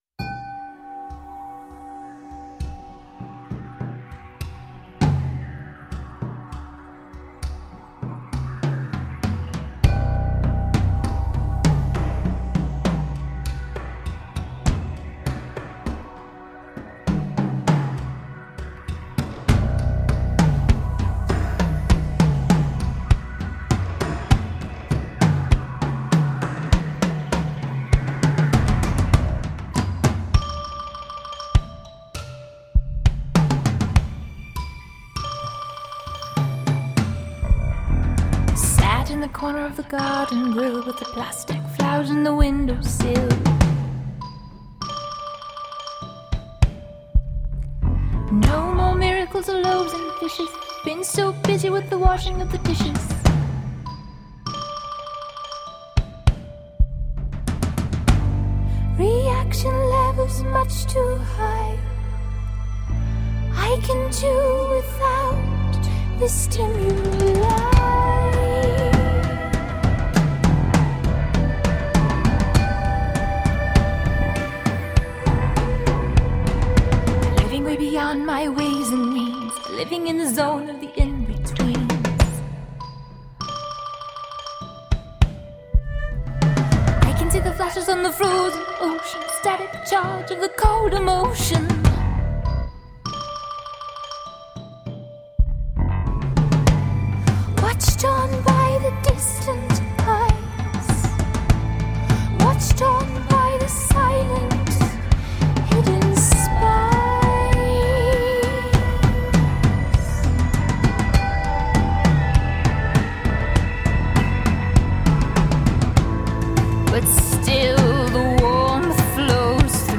Pretty pop that sounds like jewel-tone colors.